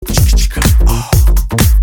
• Качество: 260, Stereo
Русский коллектив спел на испанском языке